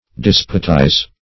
Despotize \Des"po*tize\, v. t. To act the despot.